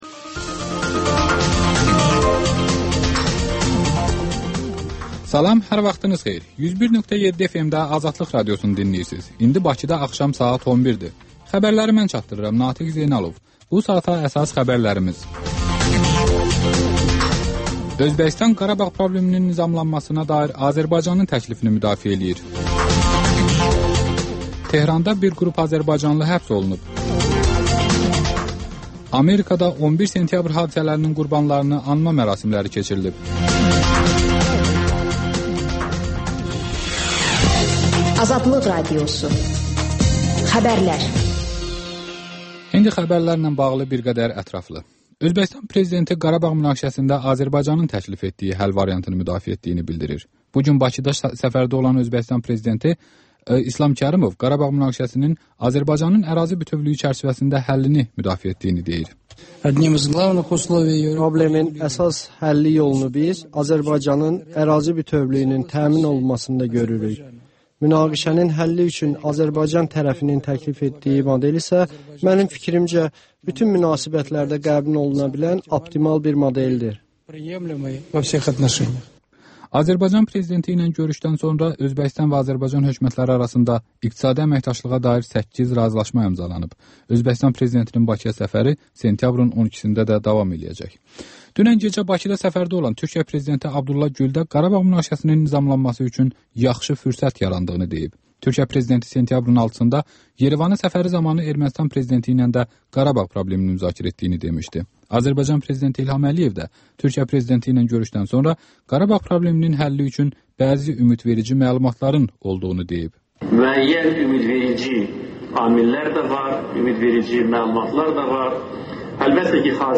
Xəbərlər, RAP-TIME: Gənclərin musiqi verilişi